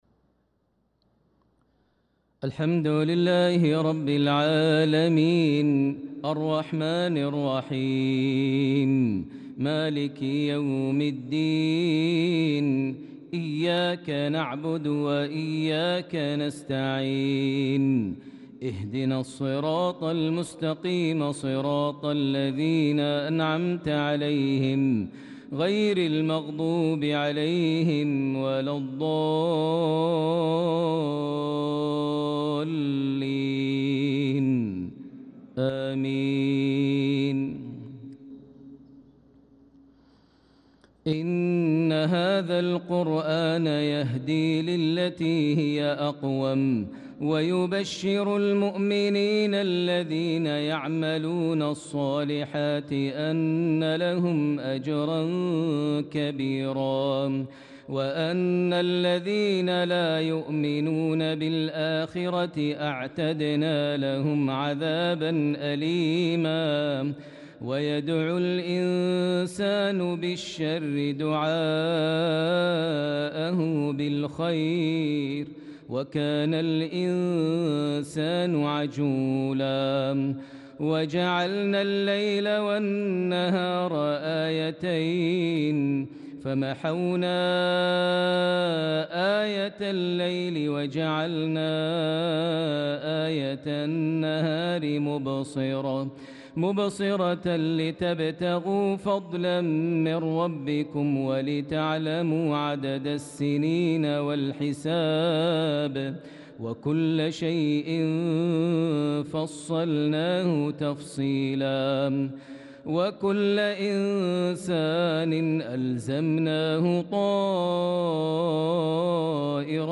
صلاة العشاء للقارئ ماهر المعيقلي 23 ذو الحجة 1445 هـ
تِلَاوَات الْحَرَمَيْن .